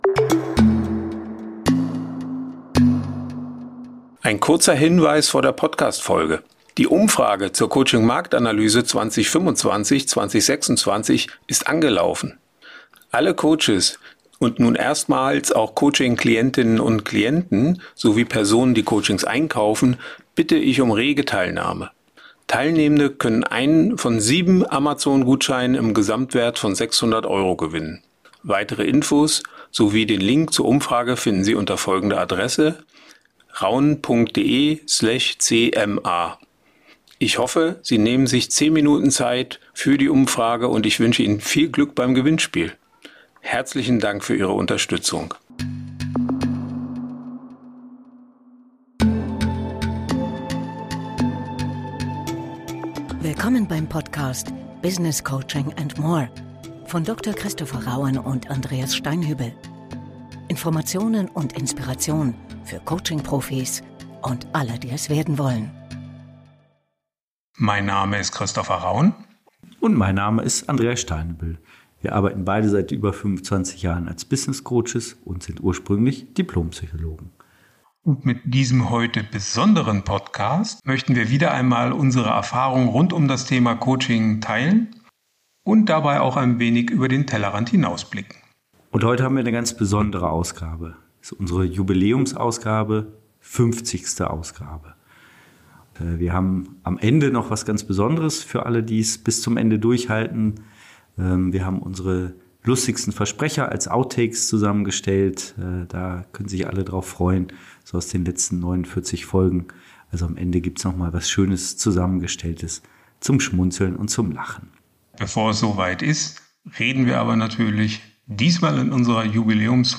Ein besonderes Extra: Am Ende der Folge hören Sie die besten Versprecher und Outtakes aus den letzten 50 Folgen.